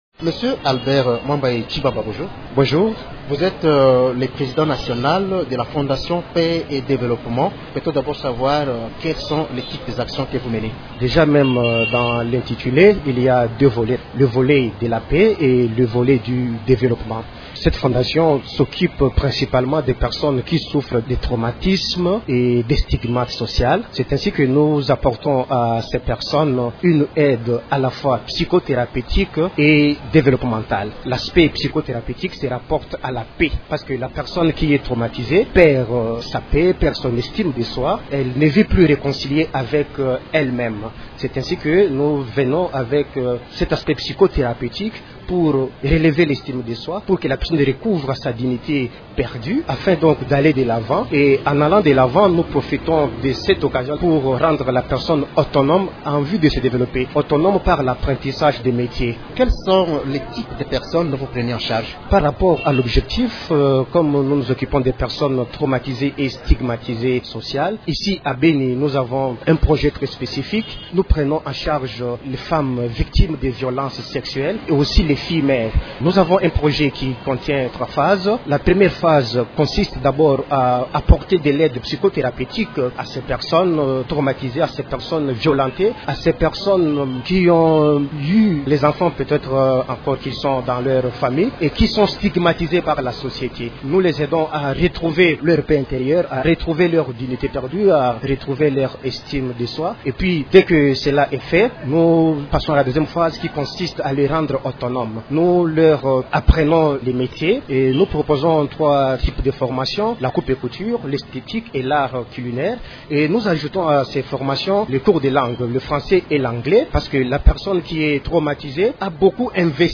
Il s’entretient avec